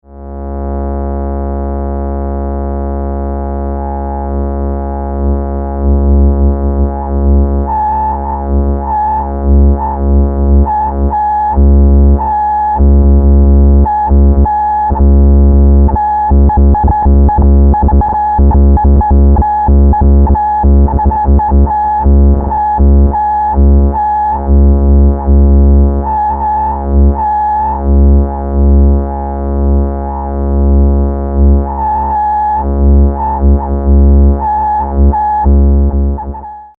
CVランダム・アウトをA-122のレゾナンスへ接続 (波形はA-111矩形波を使用)